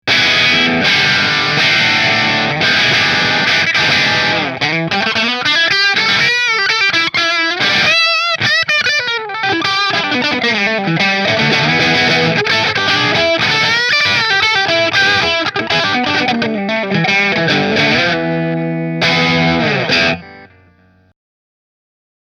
It comes with Seymour Duncan Jazz and Custom Custom pickups, as well as a Hexaphonic RCM acoustic system and a built in synth.
Godin LGX Bridge Through Marshall